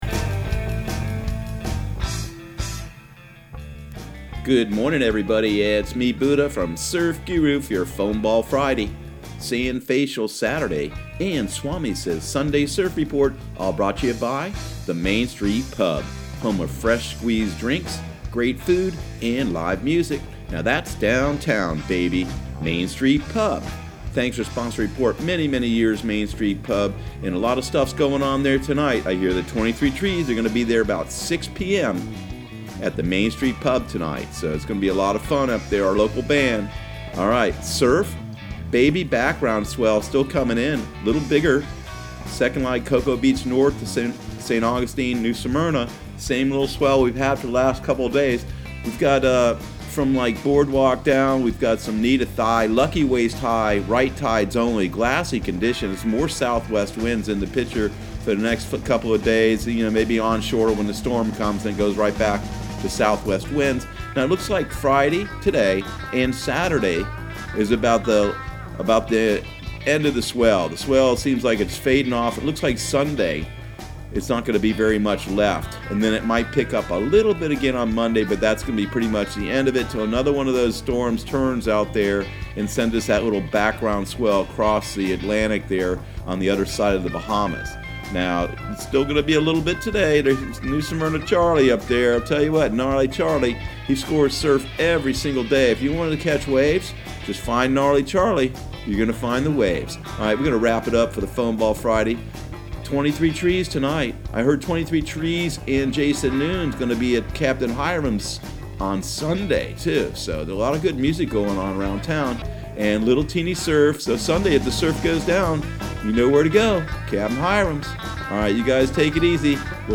Surf Guru Surf Report and Forecast 08/09/2019 Audio surf report and surf forecast on August 09 for Central Florida and the Southeast.